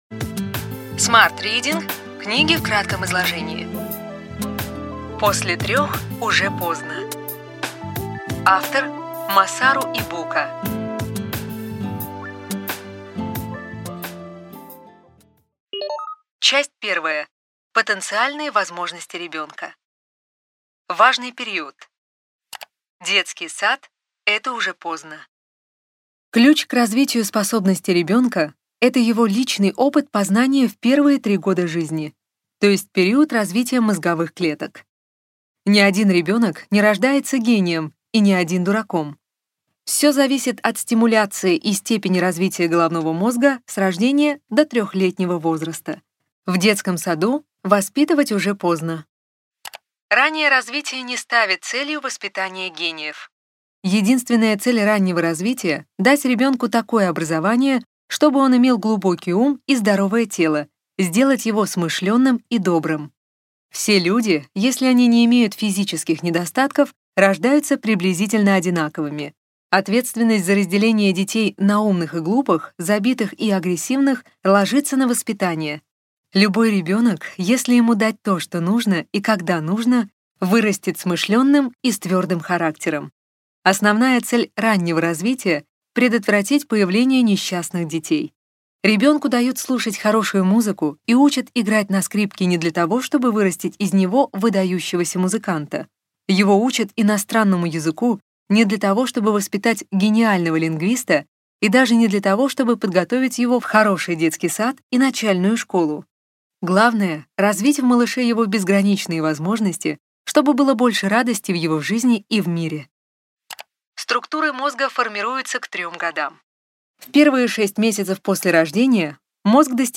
Аудиокнига Ключевые идеи книги: После трех уже поздно. Масару Ибука | Библиотека аудиокниг